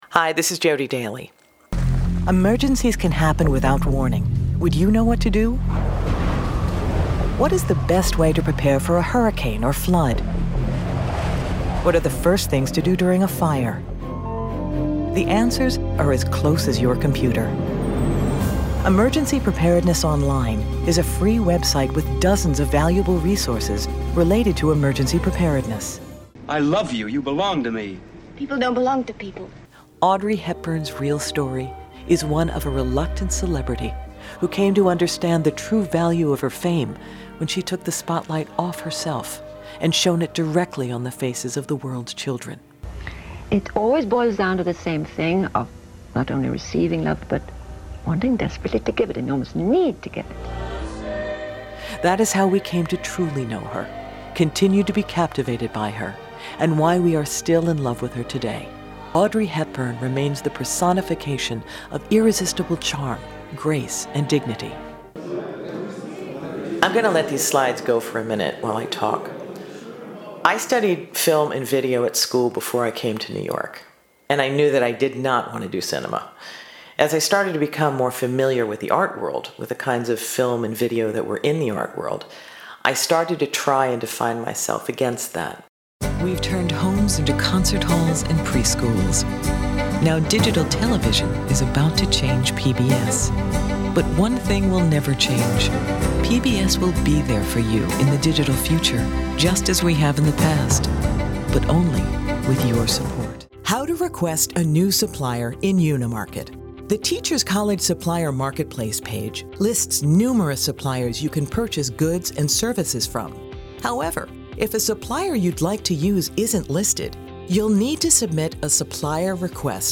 Female VOs
Listen/Download – Narration